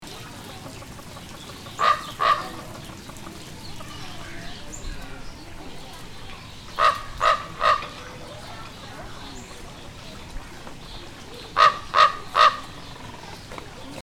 Common Raven
Voice
Common ravens are vocal animals, and have over 20 types of vocalizations for different situations. They have calls for alarm, comfort, chasing, fighting and advertising territories.
common_raven_call.mp3